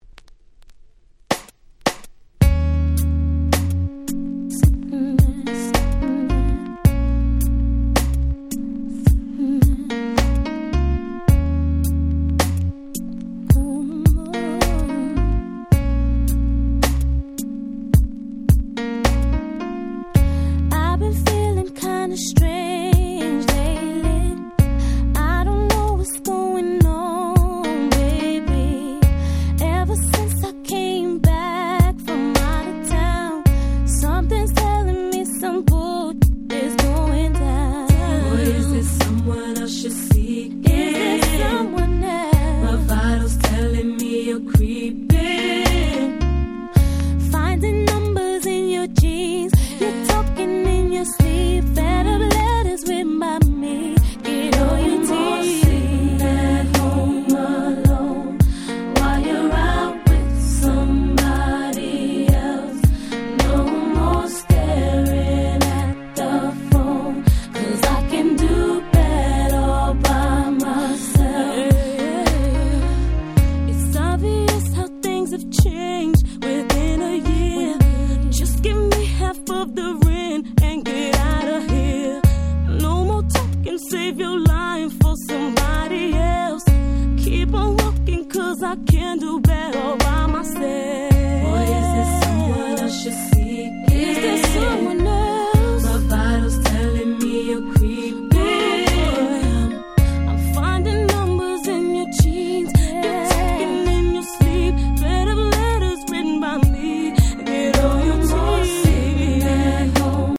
97' Super Hit R&B / Slow Jam !!
彼女たちらしいしっとりとしたSexyなスロウジャム。